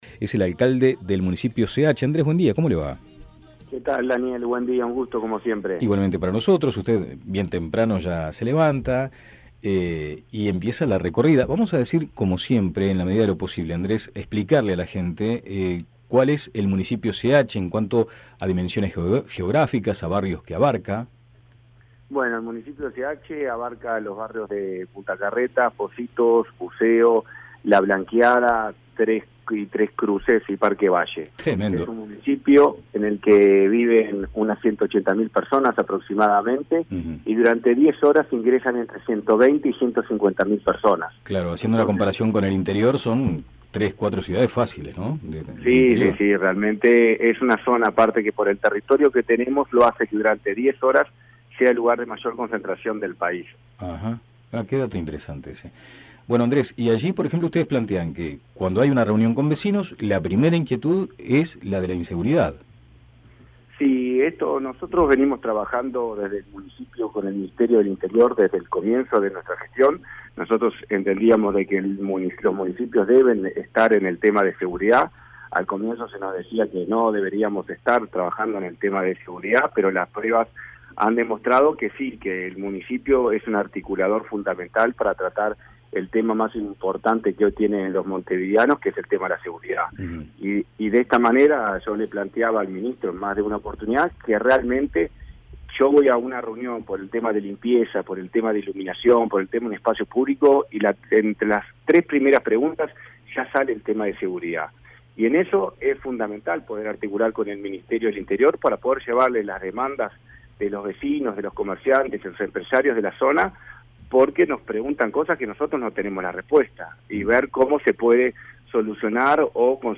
El alcalde del Municipio CH, Andrés Abt, explicó en Índice 810 que los jerarcas municipales plantearon la preocupación de varios comerciantes al ministro del Interior, ya que consideran que actualmente no tienen "respuesta de cómo conseguir mejoras" en el asunto.